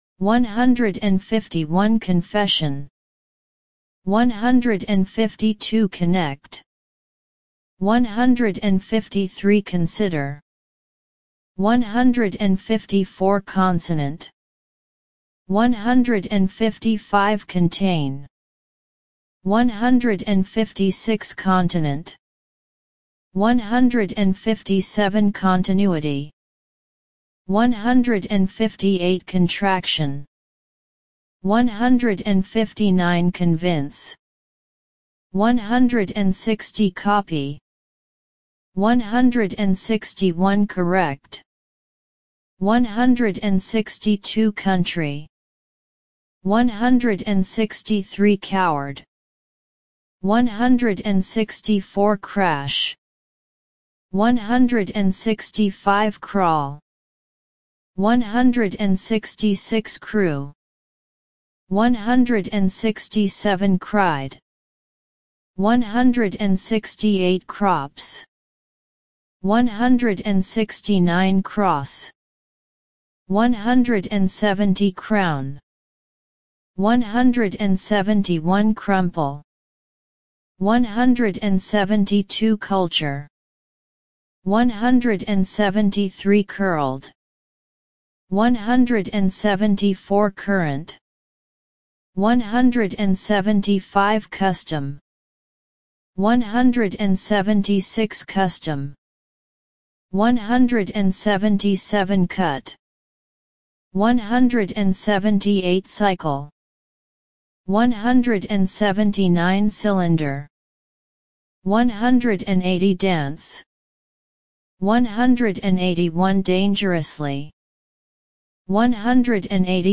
150 – 200 Listen and Repeat